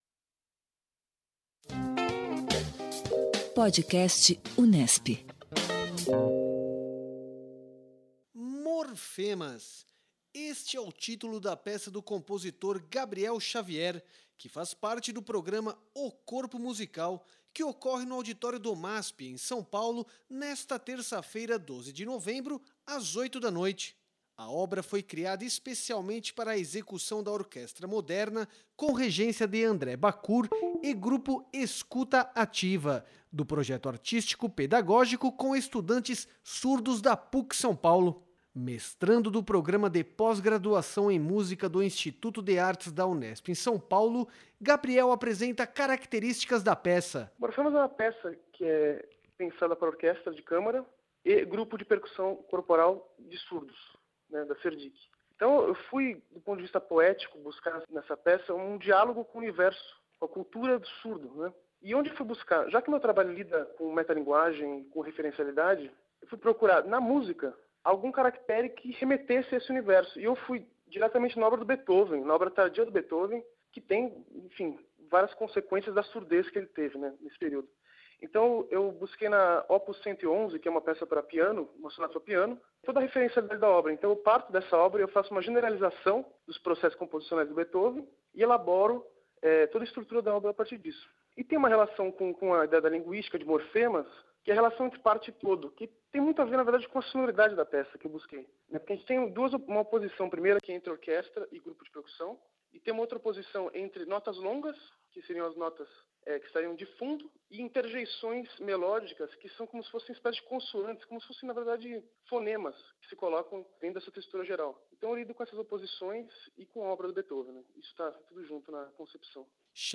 Entrevista sobre a obra Morfemas